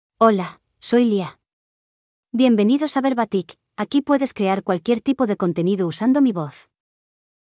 Lia — Female Spanish (Spain) AI Voice | TTS, Voice Cloning & Video | Verbatik AI
Lia is a female AI voice for Spanish (Spain).
Voice sample
Listen to Lia's female Spanish voice.
Female
Lia delivers clear pronunciation with authentic Spain Spanish intonation, making your content sound professionally produced.